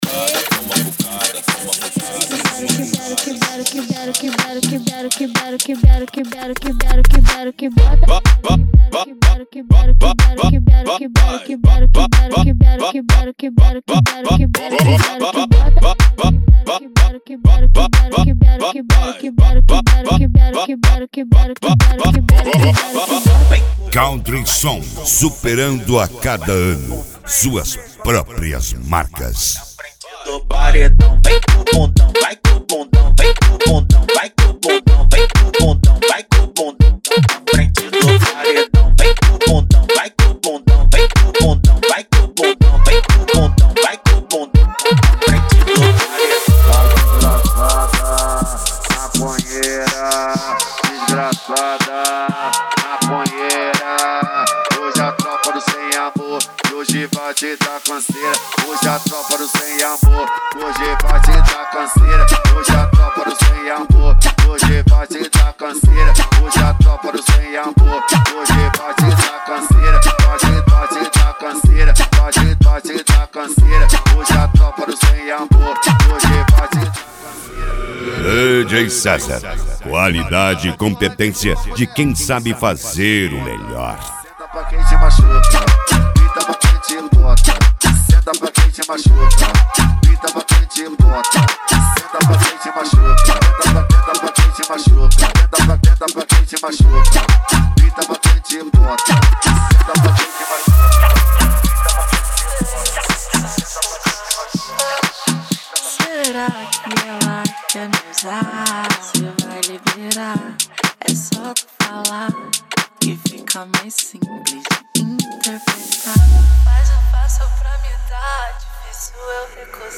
Funk
Funk Nejo
Mega Funk